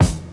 • 2000s Shiny Kick Single Hit F Key 59.wav
Royality free bass drum tuned to the F note. Loudest frequency: 948Hz